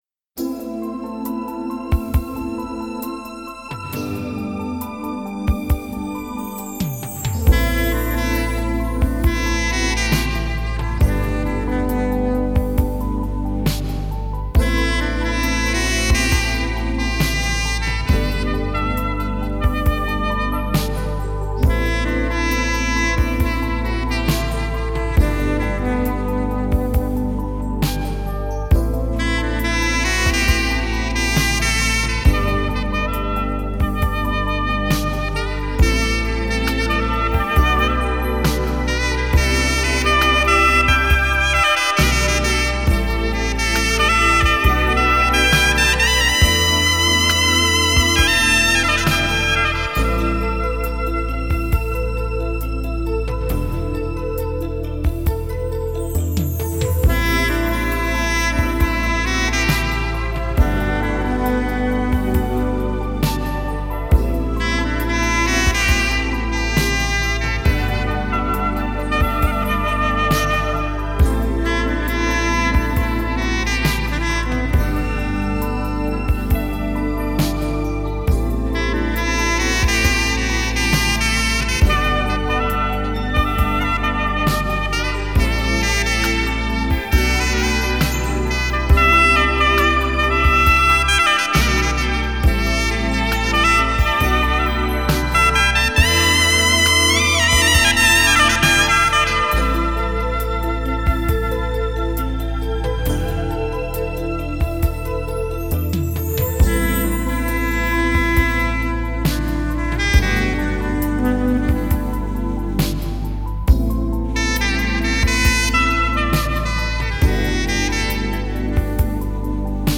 3周前 纯音乐 9